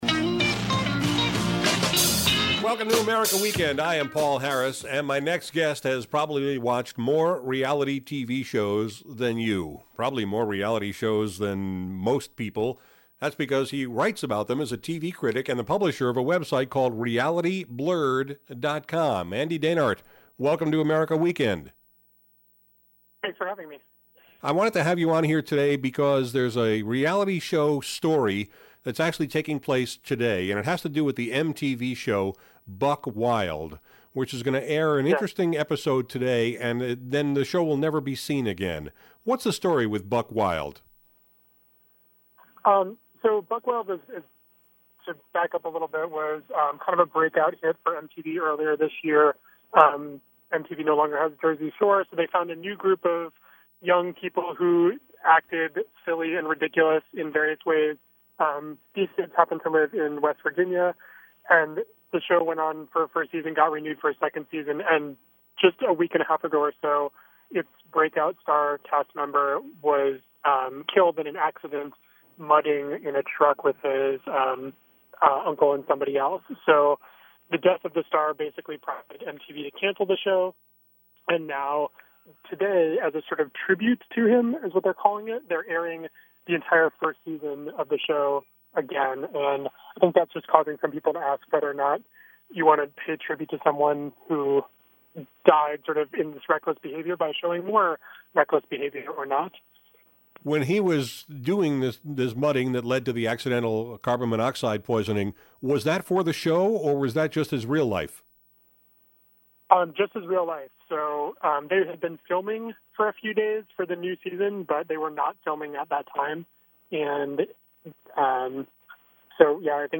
I invited him to join me on America Weekend to talk about the end of MTV’s “Buck Wild,” the decline in quality of CBS’ “Survivor,” and a complaint he has about ABC’s “Splash.”